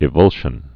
(ĭ-vŭlshən)